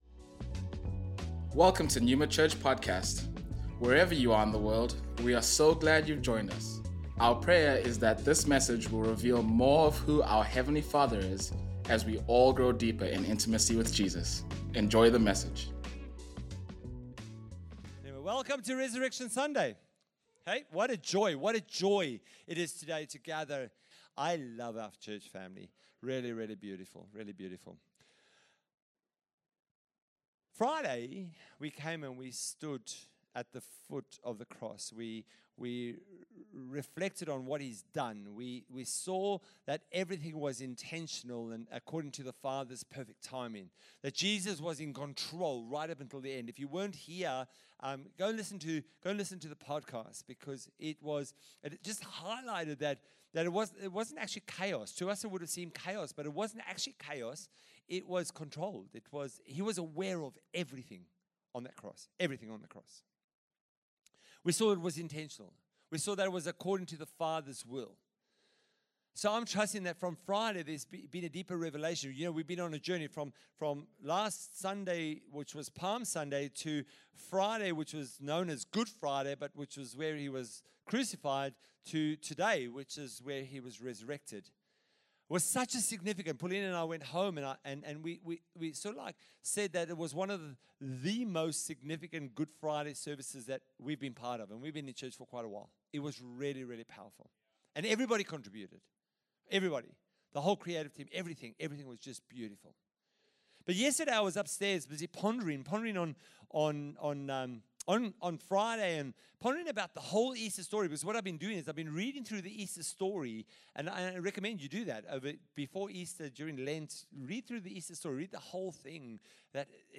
Resurrection Sunday / Easter